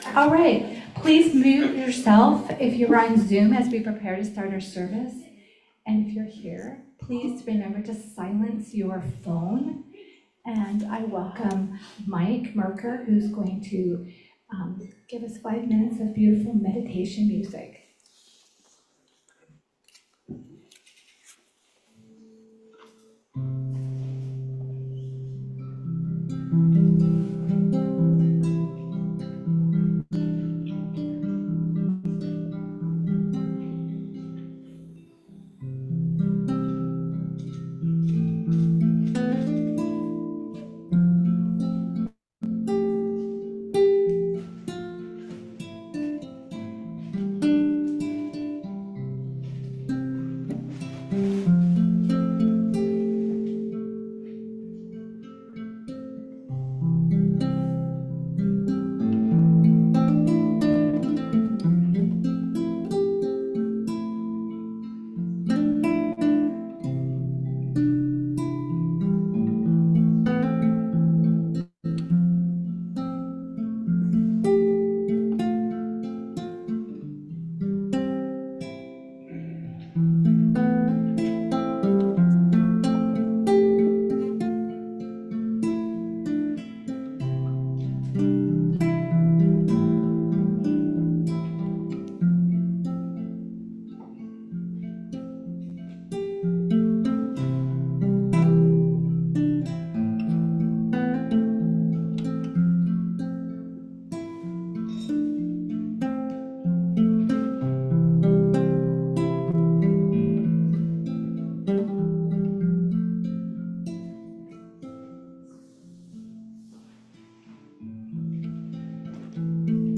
January 5, 2025 Service